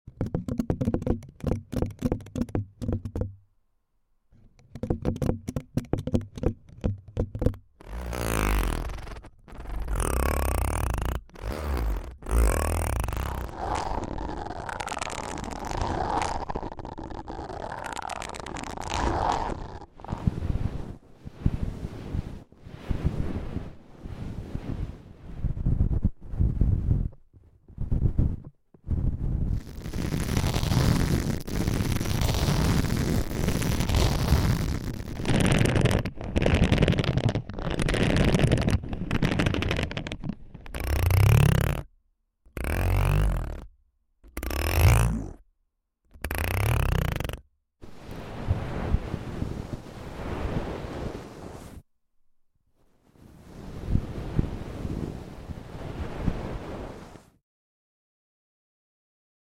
Brain Massage ASMR!